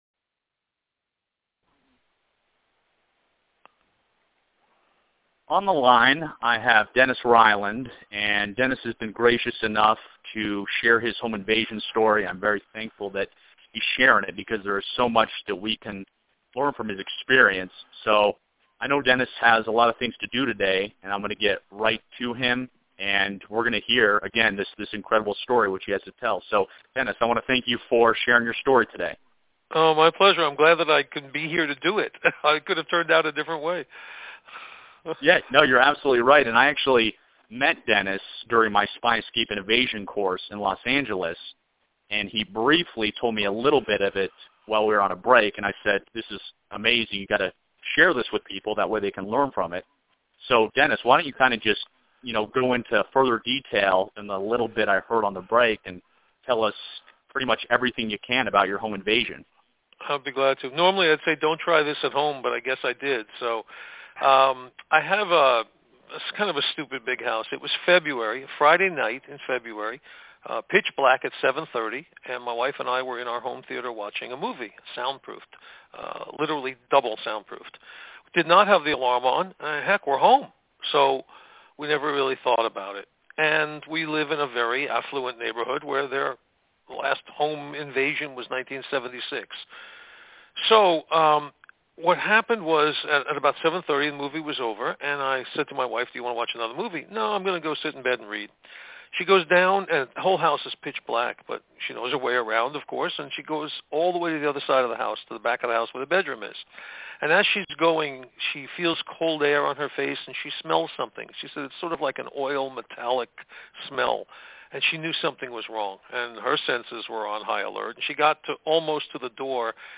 Home Invasion Survivor Interview Audio